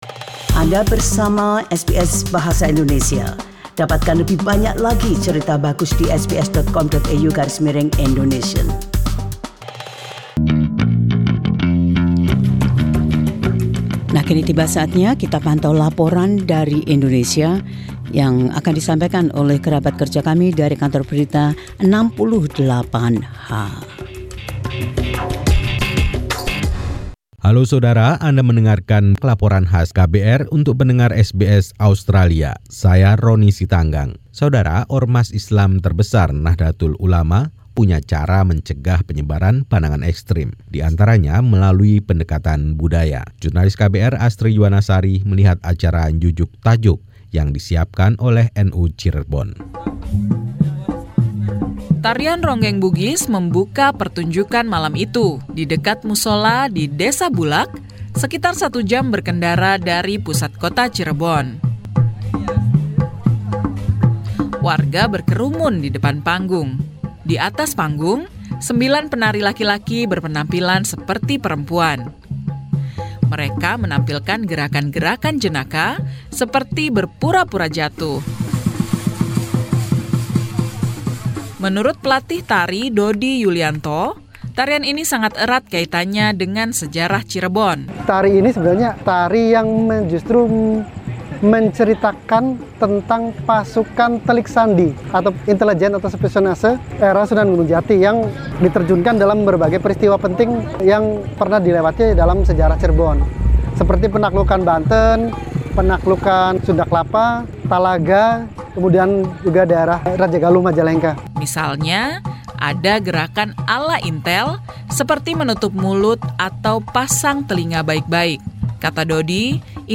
Laporan KBR 68H: Menggunakan seni untuk mencegah ekstrimisme.